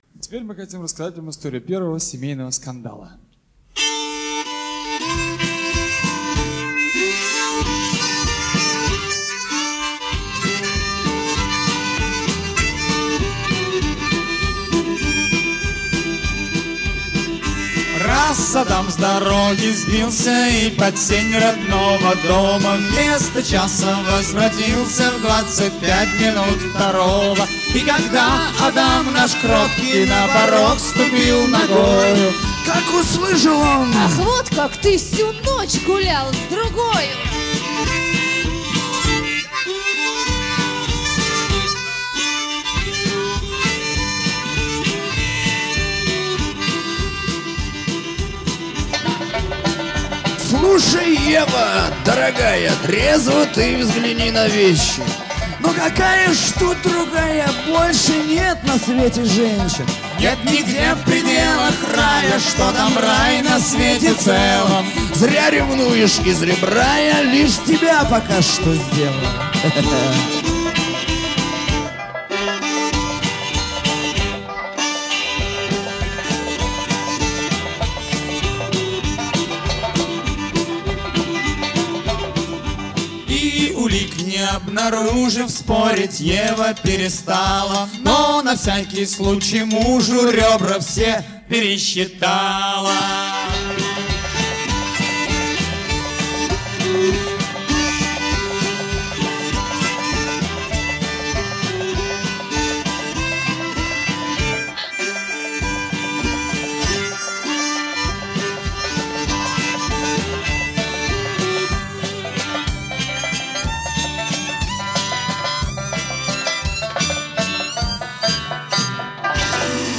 Поэтому доминировал стиль кантри.